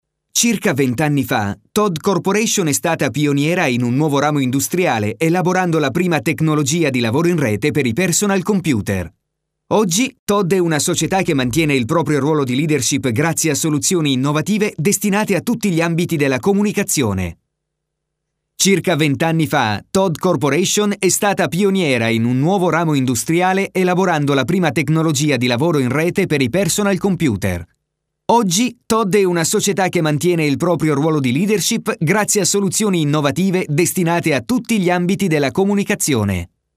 Voce giovane, adatta a comunicati dinamici, che richiedono un forte impatto energetico oppure per comunicati capaci di attirare l'attenzione con un messaggio chiaro, profondo e convincente.
Sprechprobe: Industrie (Muttersprache):
A new and fresh voice for your commercial, promos and any more!